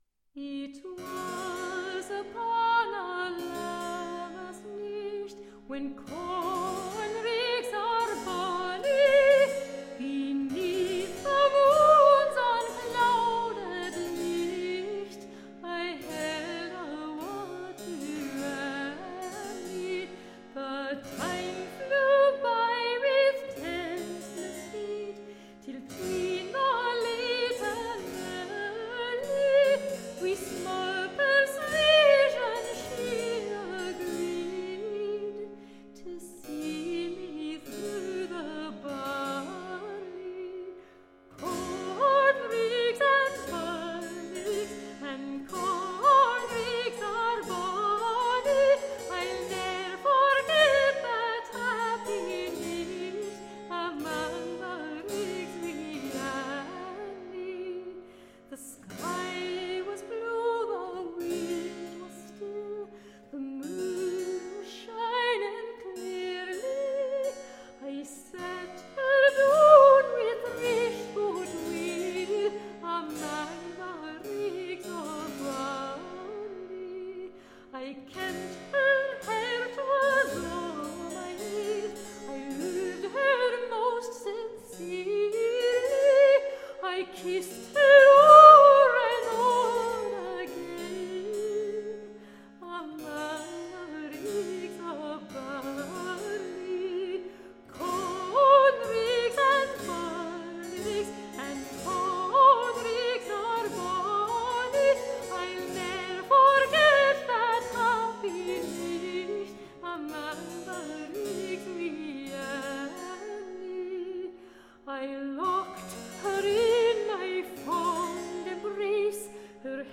Tagged as: Classical, Folk, Choral, Celtic